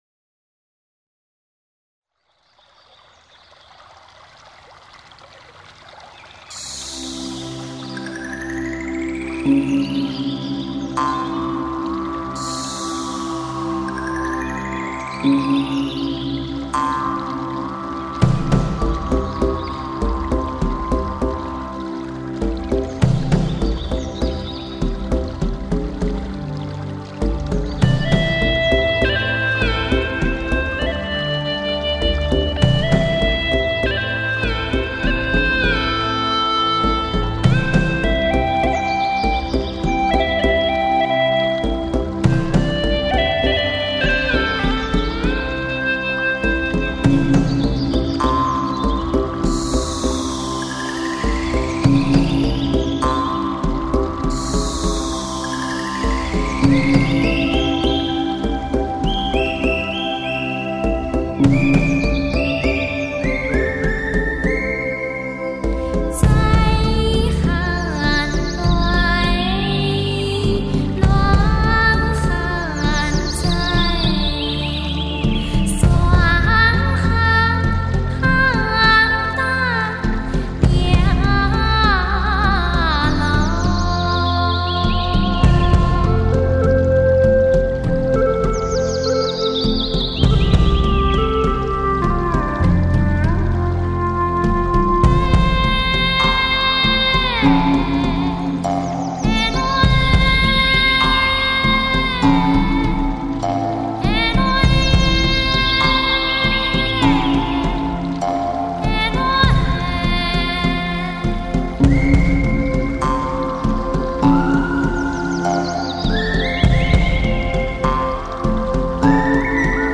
我准备发上去的全部是从正版CD抓音轨、320Kbps
挑战听觉极限，东方世界音乐发烧极品
在前卫与古老之间，发现另类世界的秘密天堂